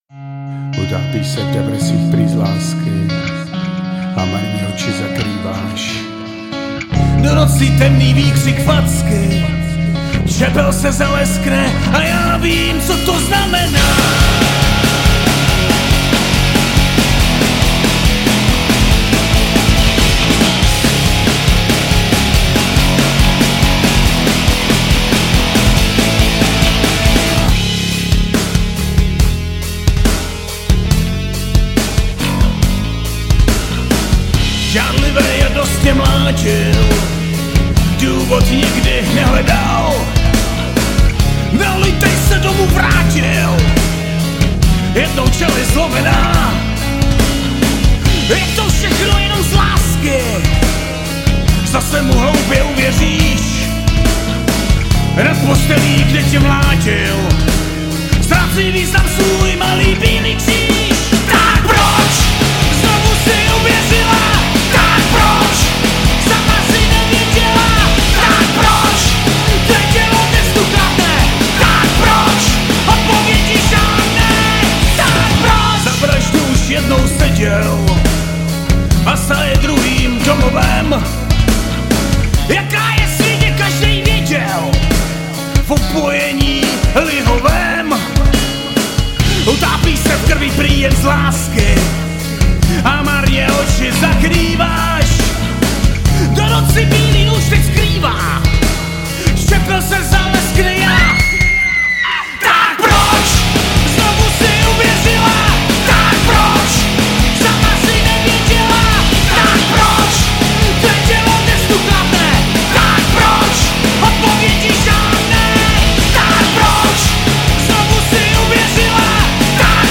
Žánr: Punk